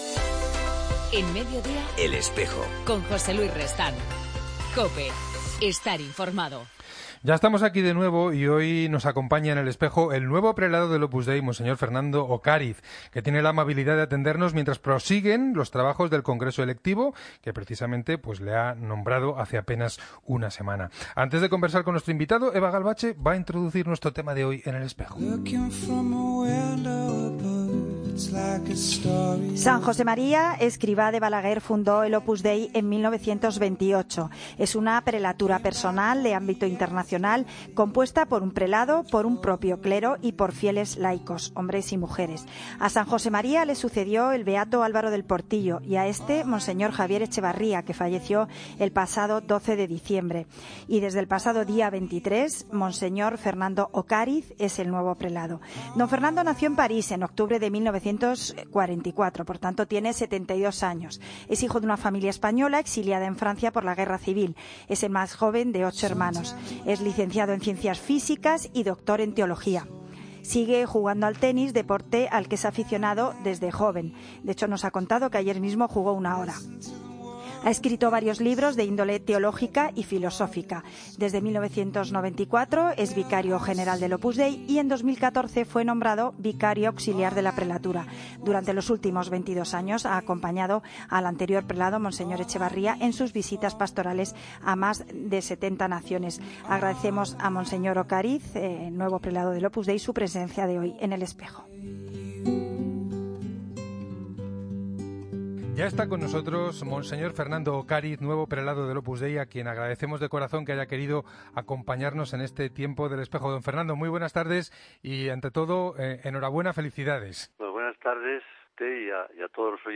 Entrevista a Mons. Fernando Ocáriz, nuevo Prelado del Opus Dei. Fuente web Opus Dei realizada por el programa El Espejo